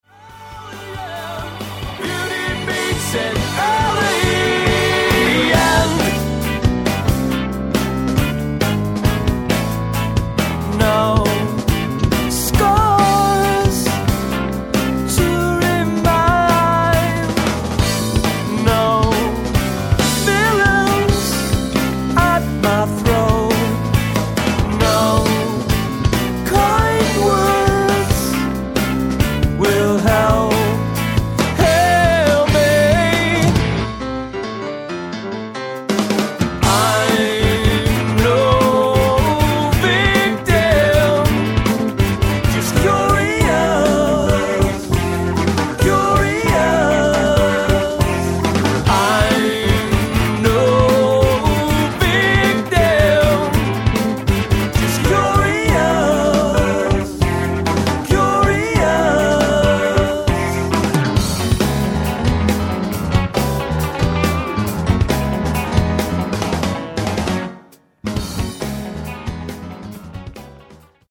keyboards, backing vocals
guitars, lead and backing vocals
drums, percussion, backing vocals
bass, backing vocals